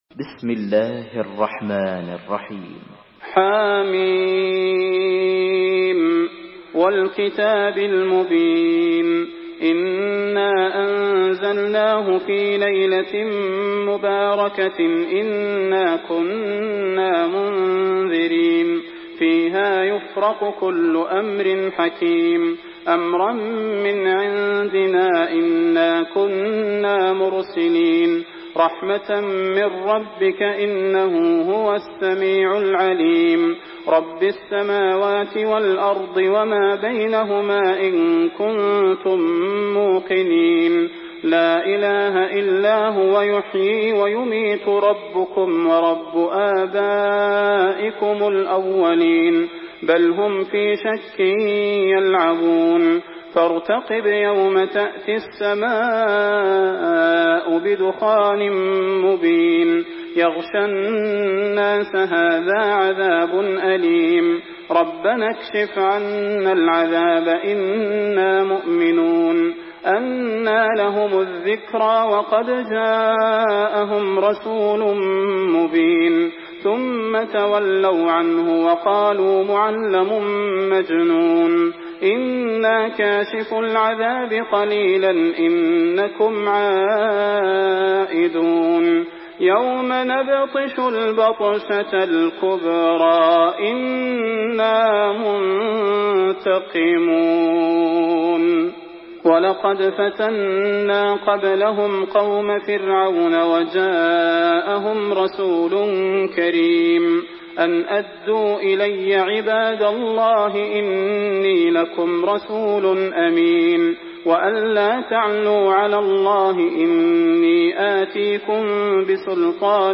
Surah Duhan MP3 by Salah Al Budair in Hafs An Asim narration.
Murattal Hafs An Asim